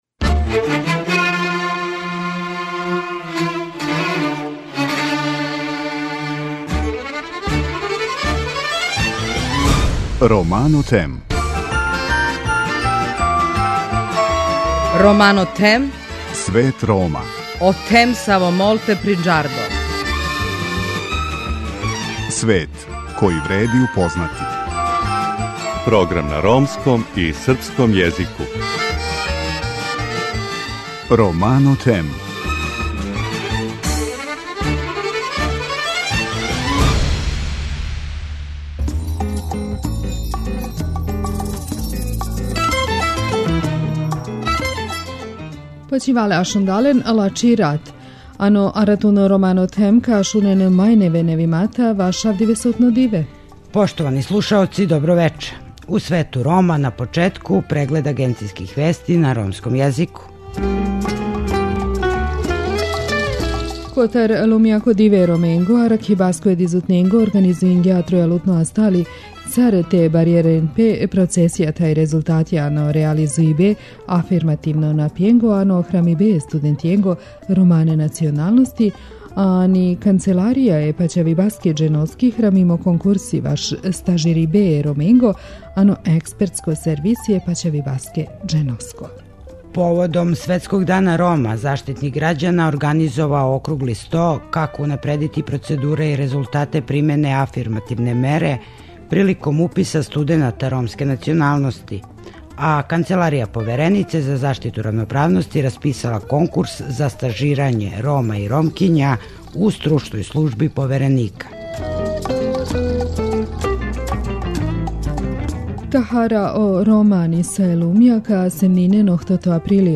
У другом делу емисије наши гости из читавог света честитају празник свим Ромима.